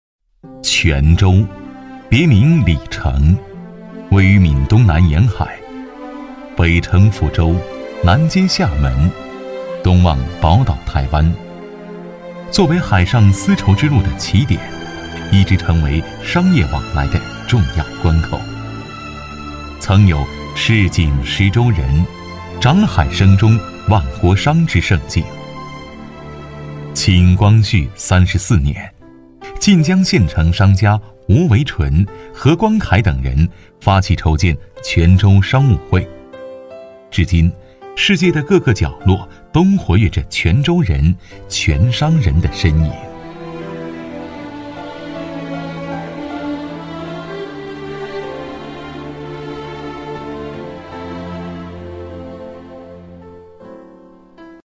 男B15-纪录片配音《泉州商会纪实》-娓娓道来.mp3